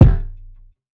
Rugged Kick.wav